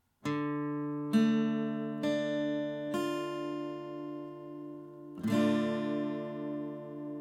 d-Moll (Offen)
D-Moll-Akkord, Gitarre
D-Moll.mp3